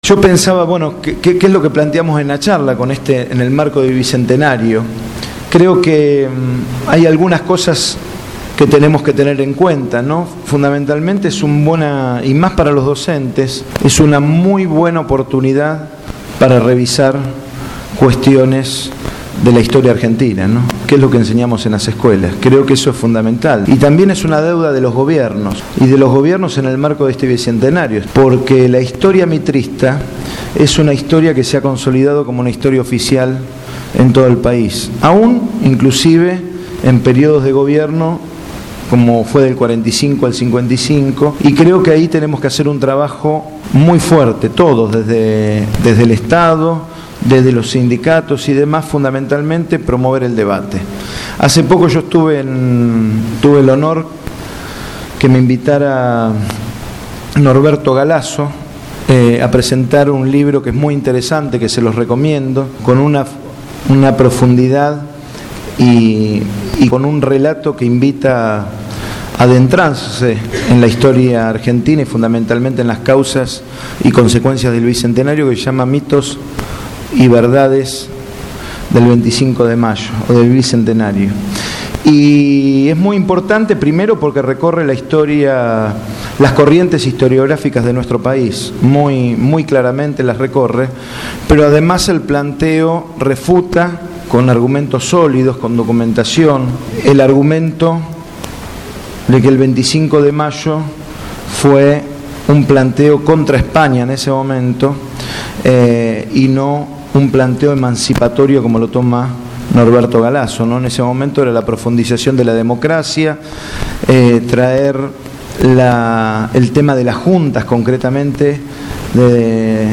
Organizado por Radio Gráfica y el programa «Cambio y Futuro», el último 19 de Mayo se realizó en la instalaciones de la radio la charla debate «Educación en el Bicentenario«.
Ante un centenar de presentes, la expectativa era mucha porque el panel de invitados así lo proponía: